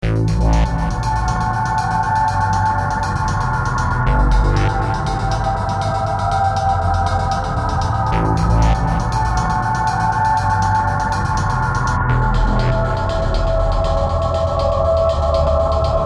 描述：桥梁张力片
Tag: 120 bpm Ambient Loops Groove Loops 2.70 MB wav Key : Unknown